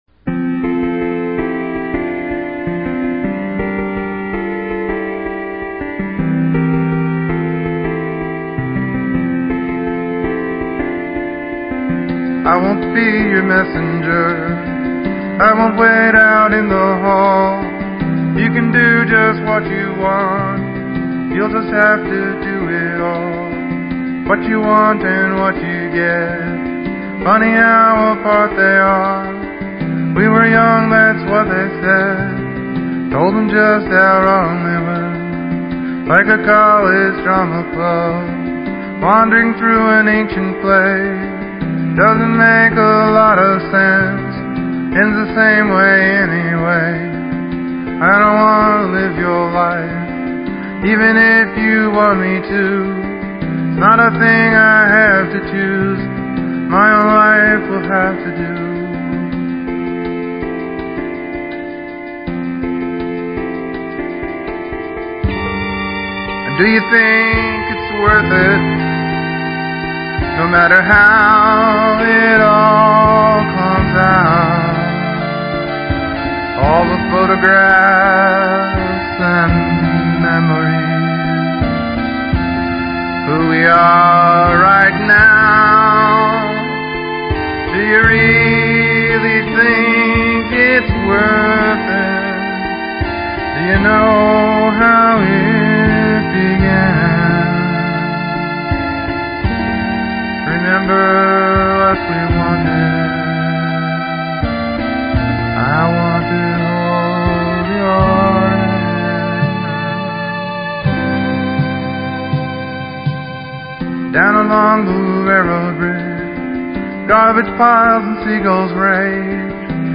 The artist stops arguing with strangers, relatives, and deities, and complains about his girlfriend, apparently using a microphone from Woolworths. ... Actually it isn’t bad; biography / slice-of-life from a particular time.